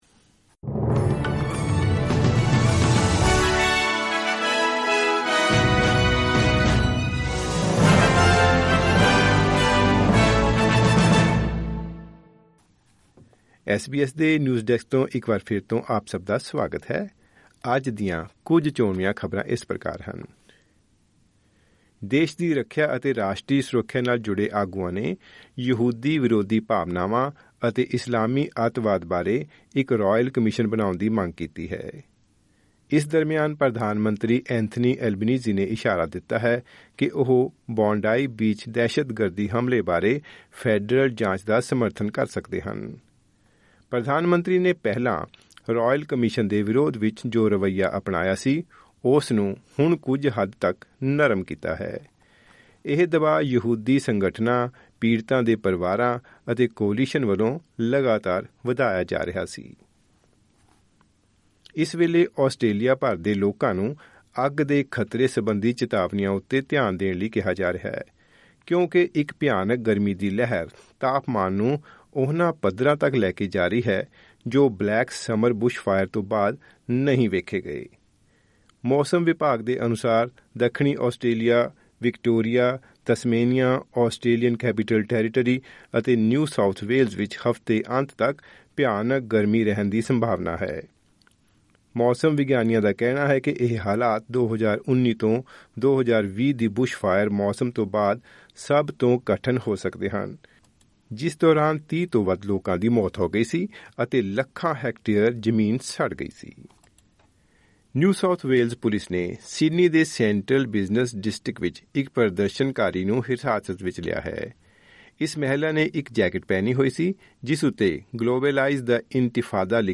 ਦੇਸ਼ ਵਿਦੇਸ਼ ਦੀਆਂ ਚੋਣਵੀਆਂ ਖਬਰਾਂ।